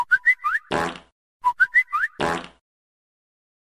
Category: Messages Ringtones